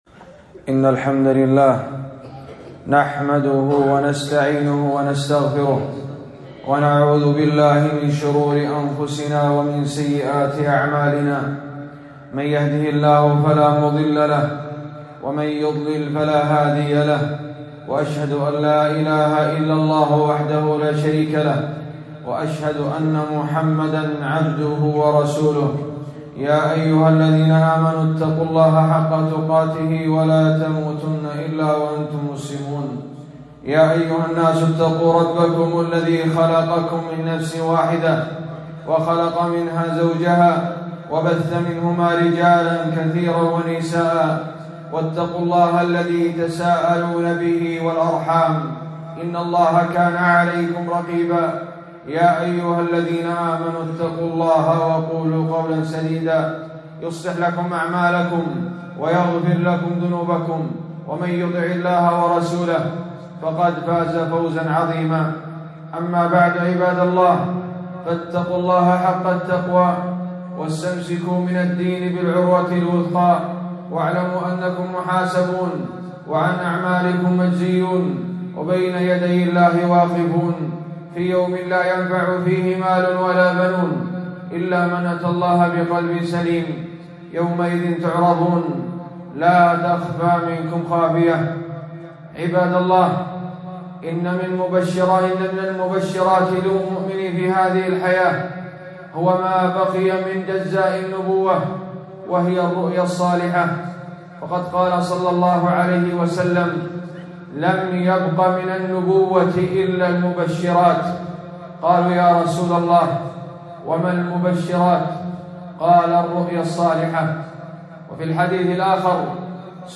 خطبة - التحذير من دجاجلة الرؤى